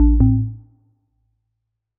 Melodic Power On 11.wav